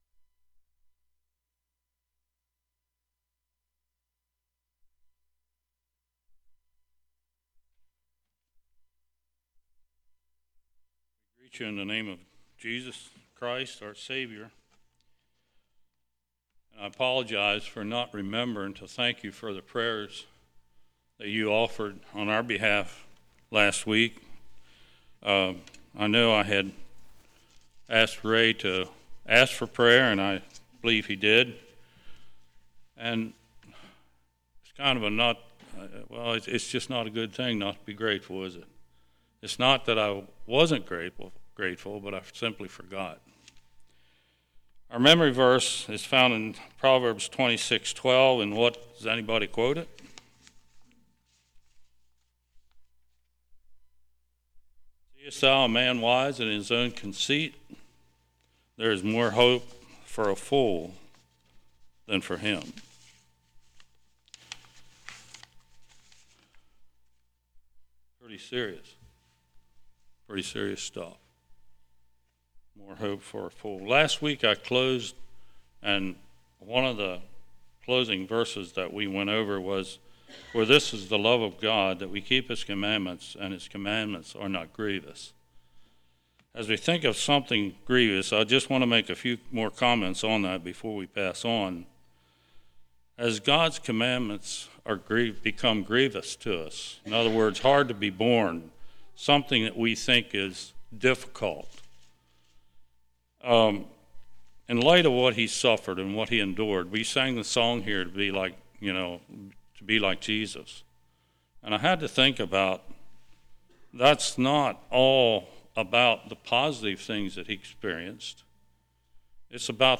Passage: Romans 11:13-21 Service Type: Winter Bible Study